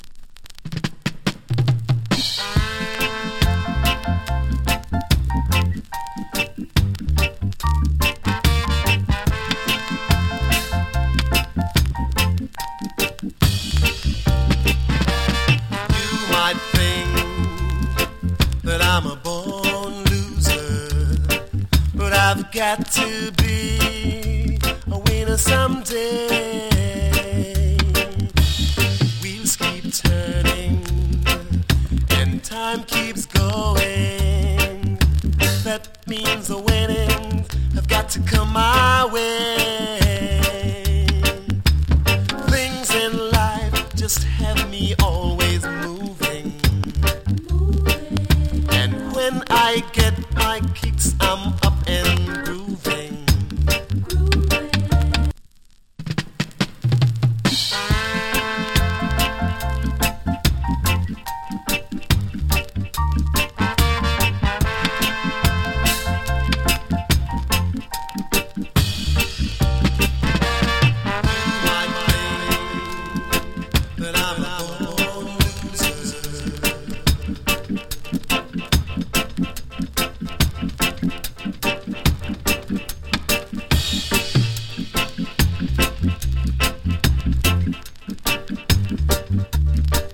7inch
チリ、ジリノイズ有り。
の NICE VOCAL REGGAE ＋ DUB.